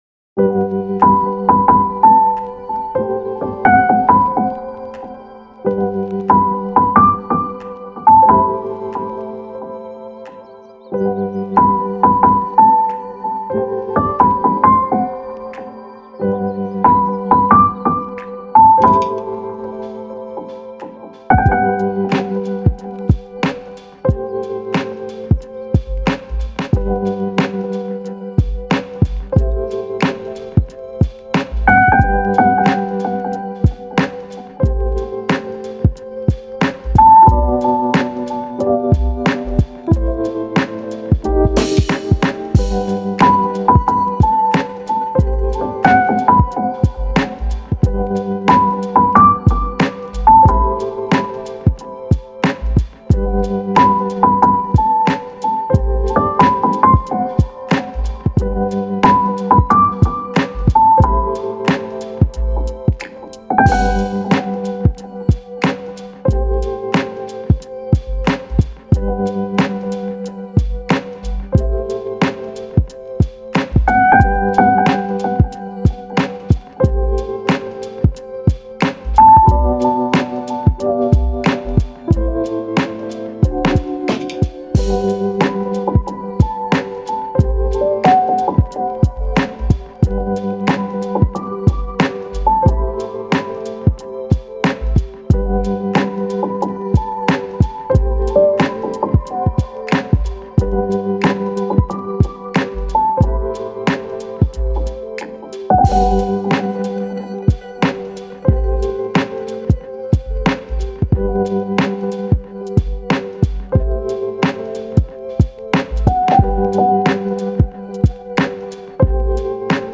チル・穏やか フリーBGM